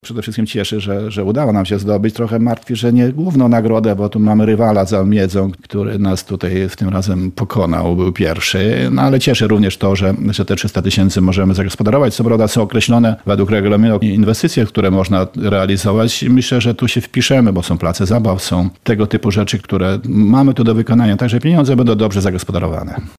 – Złożymy wniosek i mamy już pomysł na wydanie tych 300 tys. zł – mówi wójt Godziszowa Józef Zbytniewski.